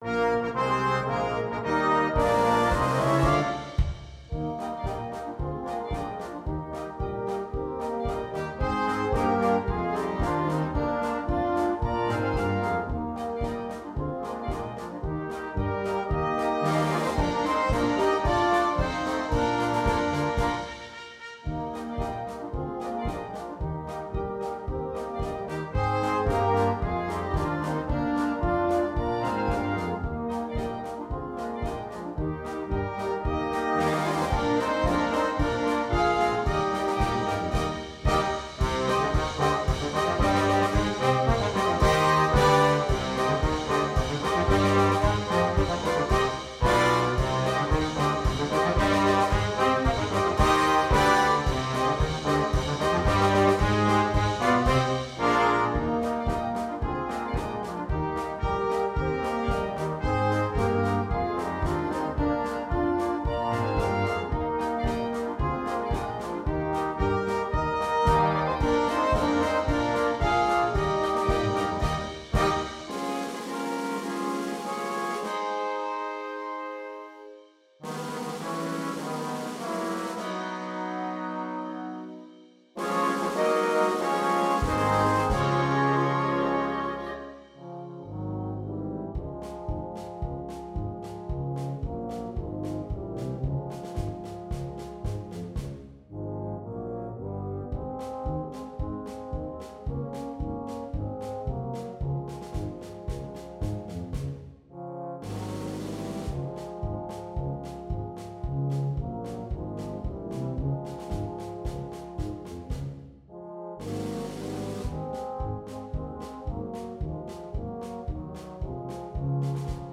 2. Blaskapelle
ohne Soloinstrument